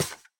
Minecraft Version Minecraft Version 1.21.5 Latest Release | Latest Snapshot 1.21.5 / assets / minecraft / sounds / block / spawner / step3.ogg Compare With Compare With Latest Release | Latest Snapshot